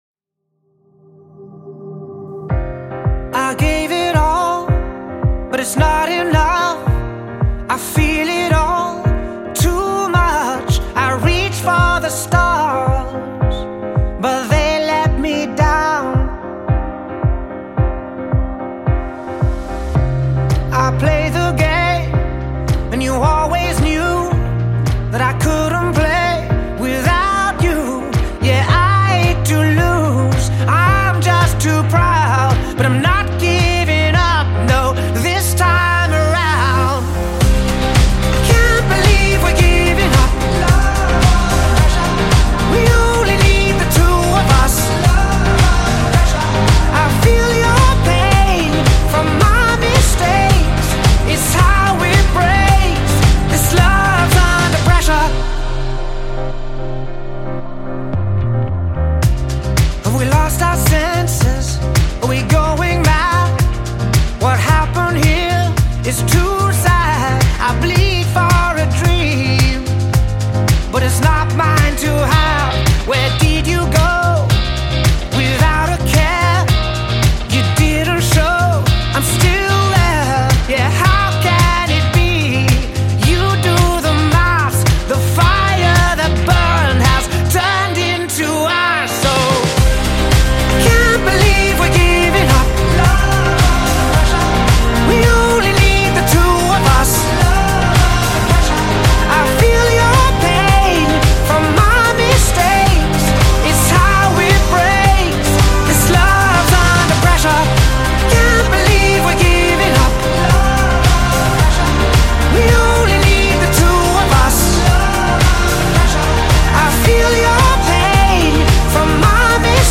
Genre : Pop.